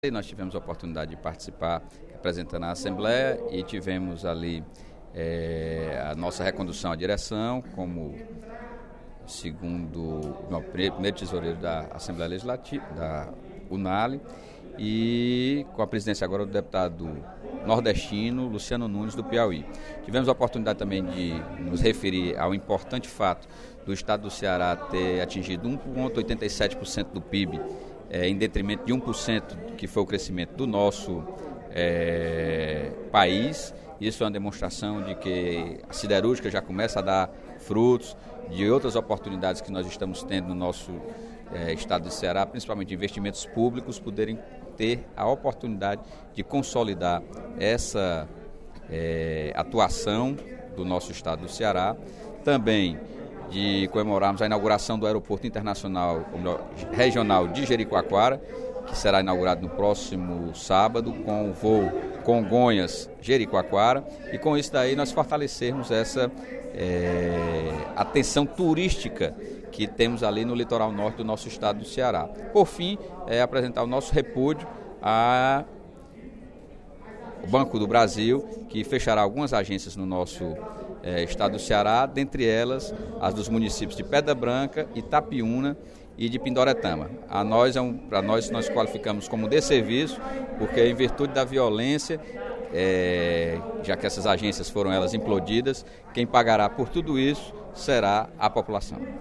O deputado Sérgio Aguiar (PDT) enfatizou, no primeiro expediente da sessão plenária da Assembleia Legislativa desta terça-feira (20/06), a participação na Conferência Nacional dos Legisladores e Legislativos Estaduais, realizada pela União Nacional dos Legisladores e Legislativos Estaduais, em Foz do Iguaçu, nos dias 7, 8 e 9 de junho.